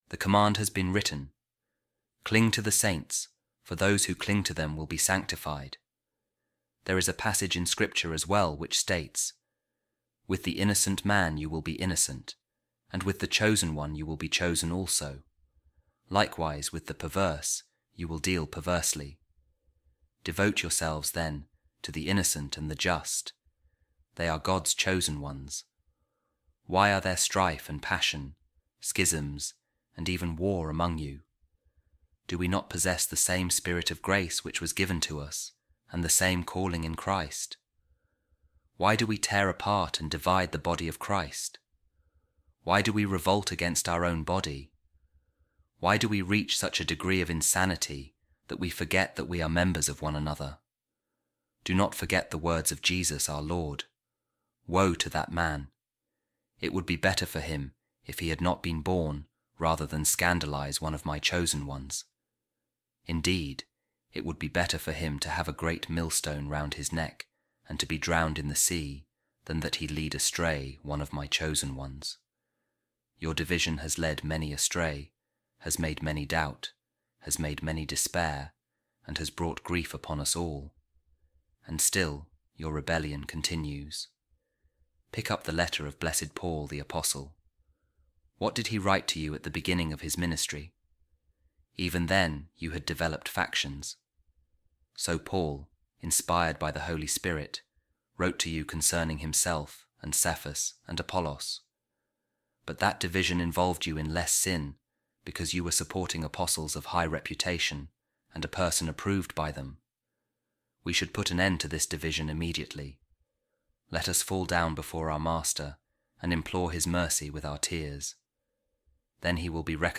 A Reading From The Letter Of Pope Saint Clement I To The Corinthians | The Communal Good